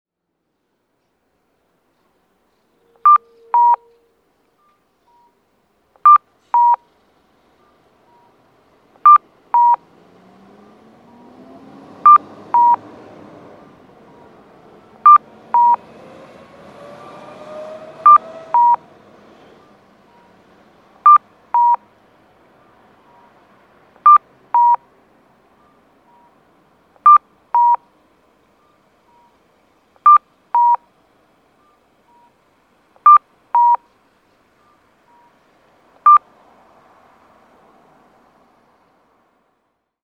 交通信号オンライン｜音響信号を録る旅｜山口県の音響信号｜[21006]東萩駅前交差点
東萩駅前交差点(山口県萩市)の音響信号を紹介しています。